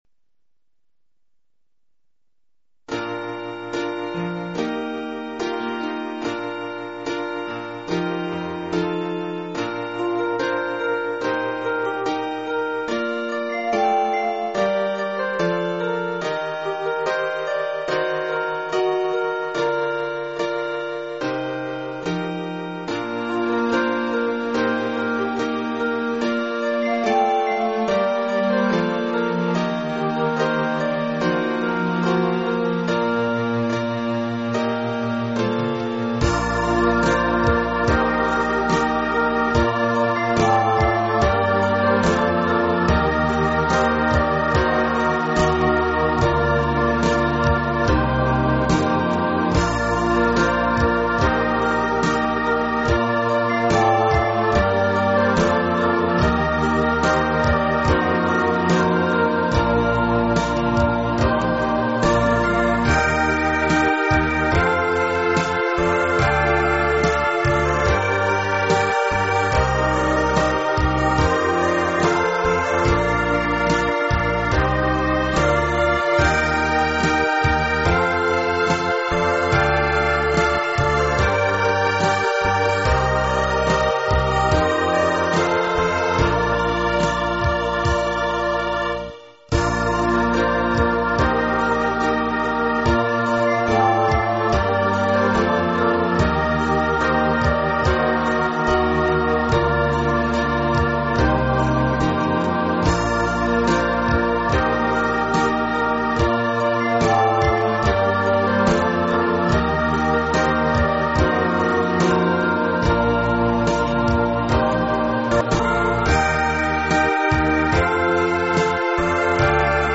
караоке